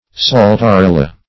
Saltarella \Sal`ta*rel"la\, n.